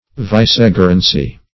\Vice*ge"ren*cy\